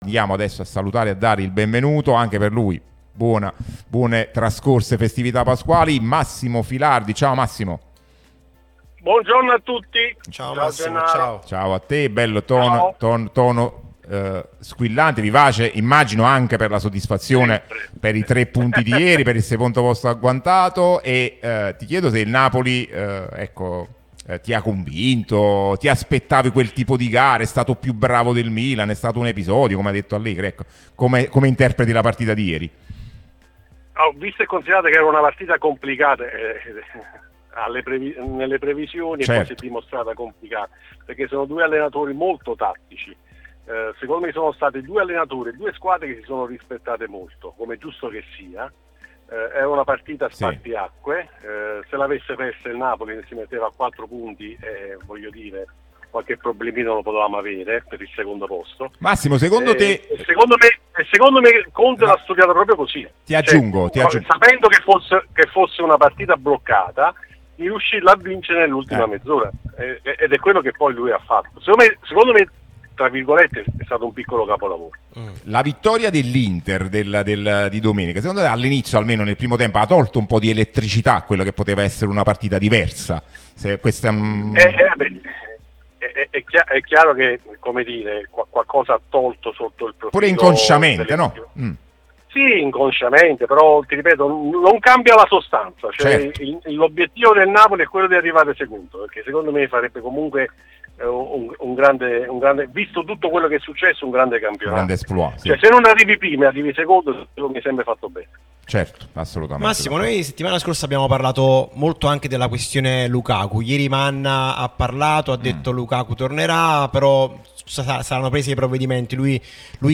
Radio Tutto Napoli